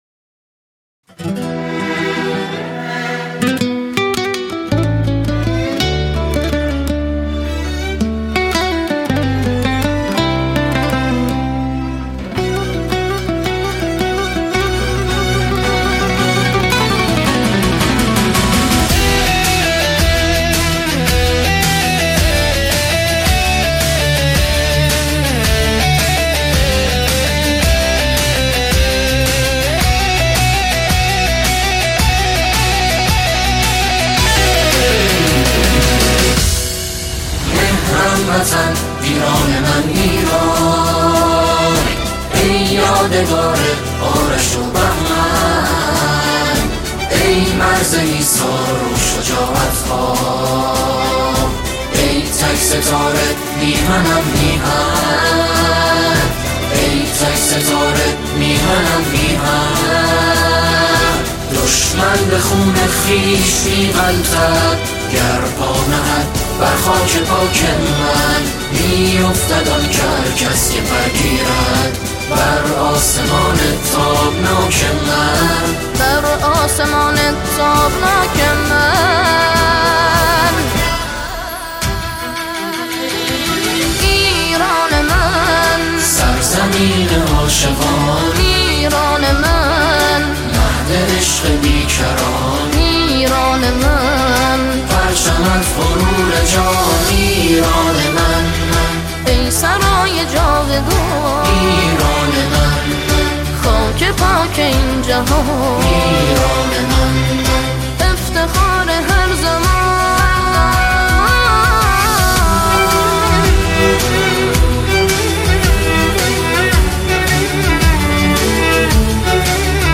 ژانر: سرود ، سرود انقلابی